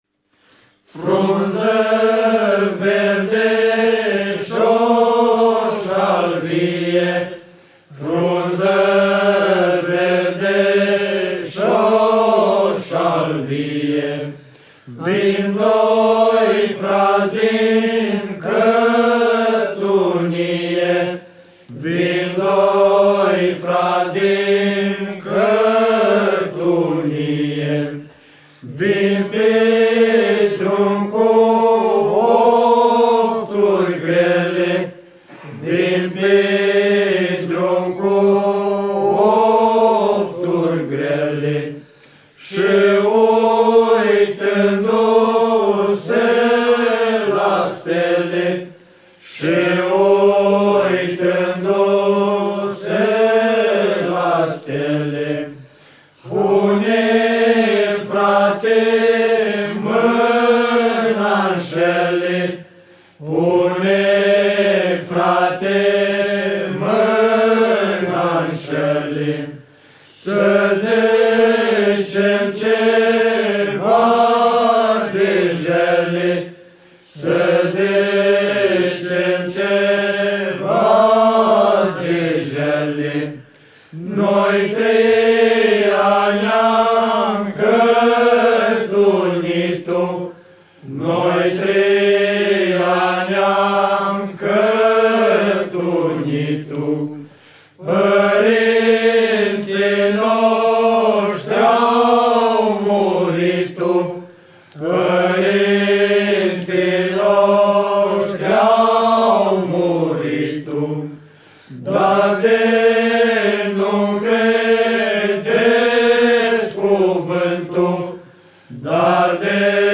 Cântecul de cătănie pe care l-aţi ascultat, prezintă legătura puternică dintre om şi natură, codru, unde doi fraţi de sânge se întorc pe plaiurile părinteşti, dar of-urile lor sunt încărcate de un puternic sentiment lirico-dramatic, tensiunea crescând pe măsură ce aceştia pătrund în lumea satului.